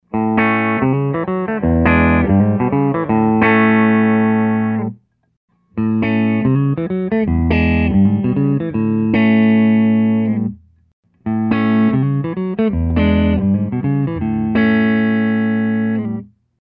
Pickin , pickup selection changes from bridge to both to neck.